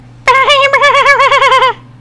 infinitefusion-e18/Audio/SE/Cries/VIBRAVA.mp3 at releases-April